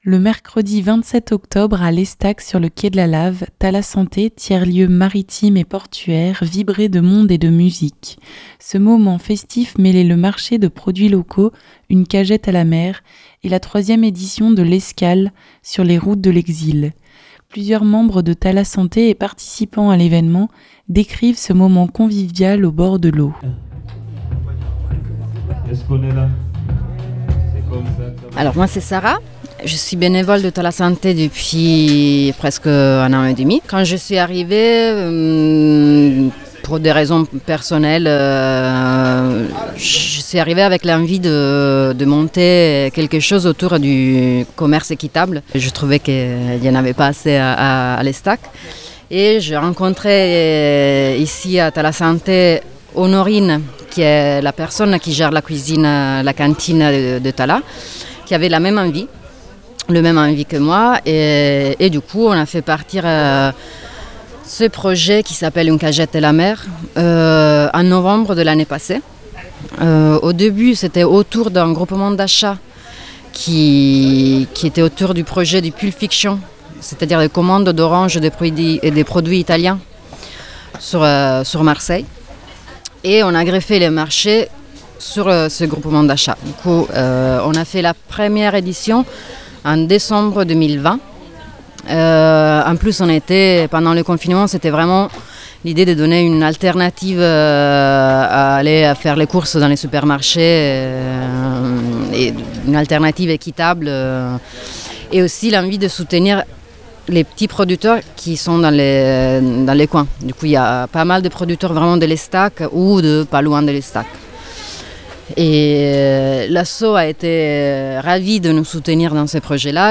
Reportage à Thalassanté (18.23 Mo)
Plusieurs membres de Thalassanté et participants à l’événement décrivent ce moment convivial au bord de l’eau.